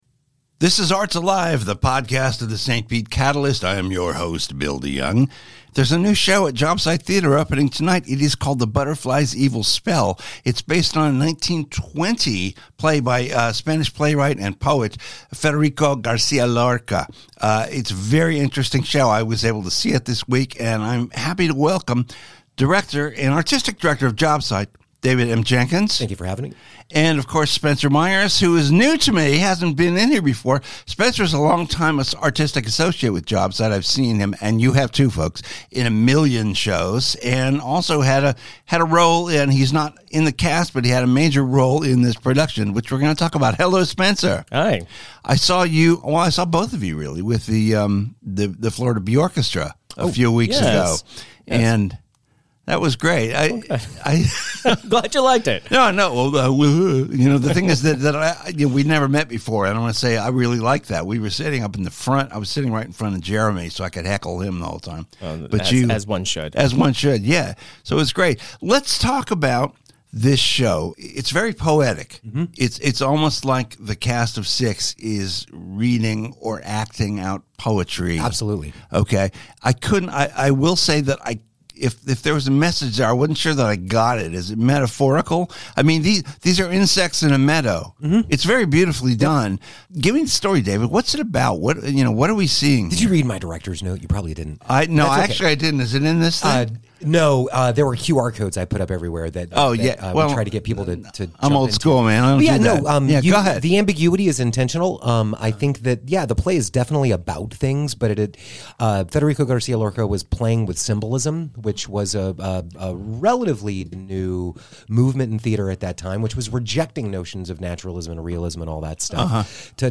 This rich collaborative tapestry forms the nucleus of today’s conversation.